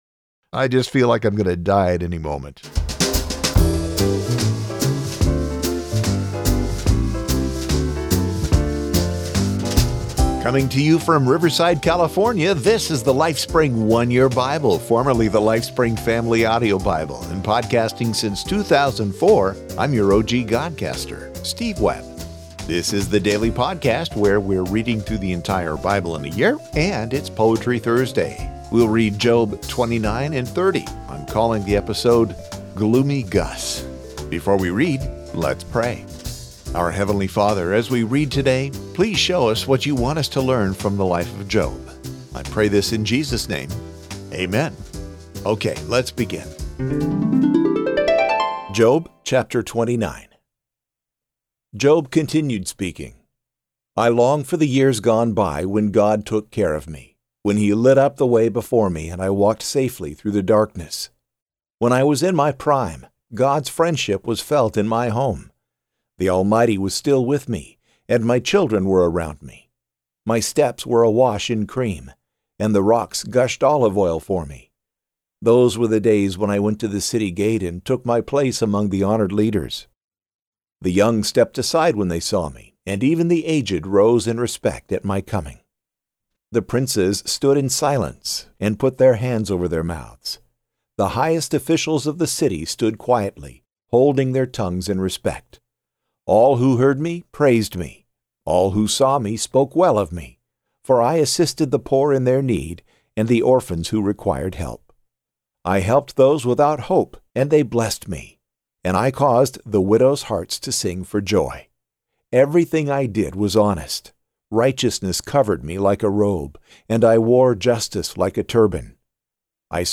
Each episode features a reading, followed by a short commentary.